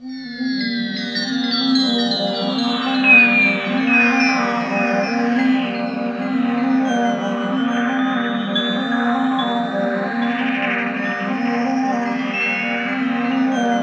SYN JD80007R.wav